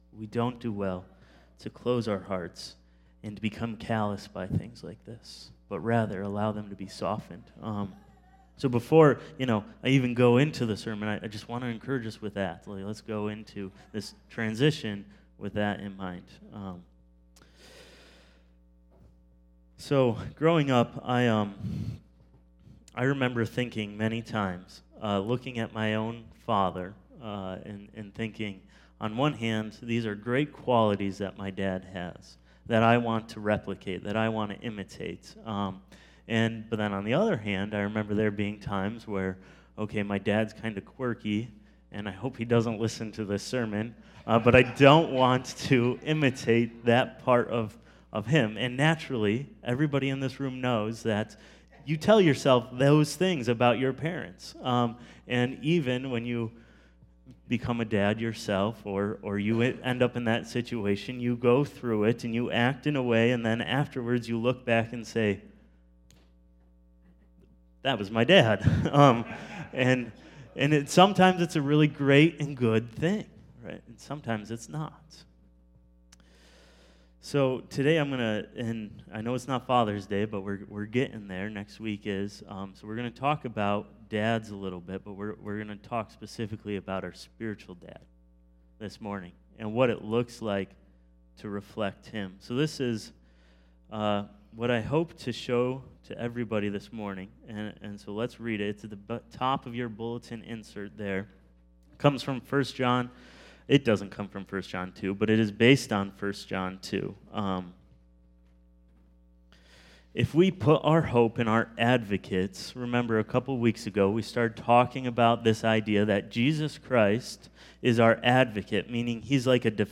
1 John 2:18-3:10 Sermon